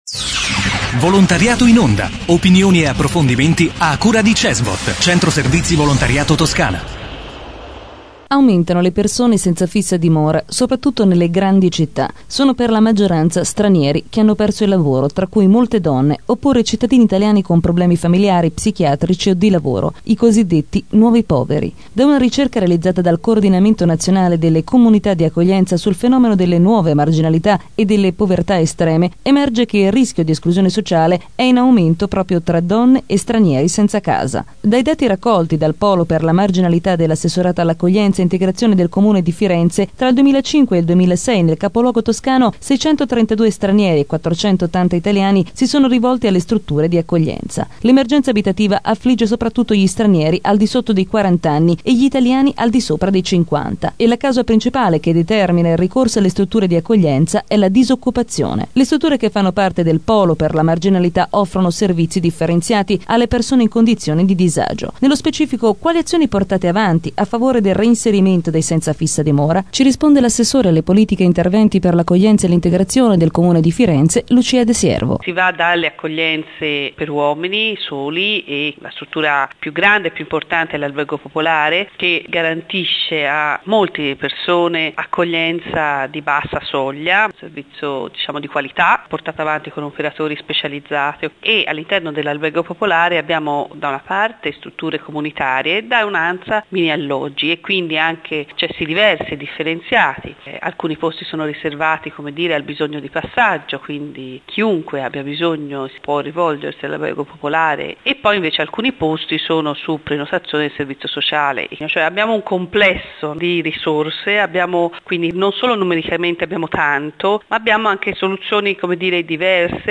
Intervista a Lucia de Siervo, assessore all'accoglienza e integrazione del Comune di Firenze